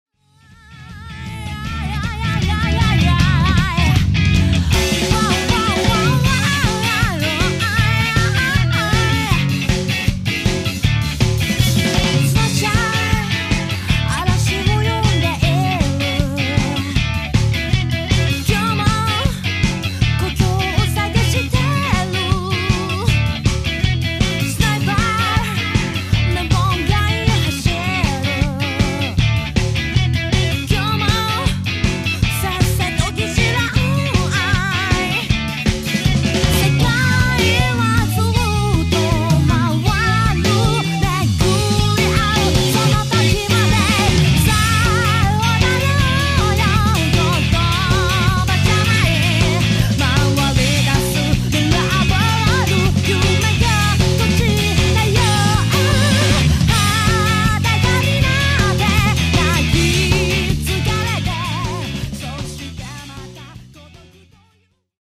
技巧に走らずセンスのいいギター。安定したビートのリズム体。そしてストレートで伸びやかなボーカル。
ヴォーカルとタイコのグルーヴ良いです。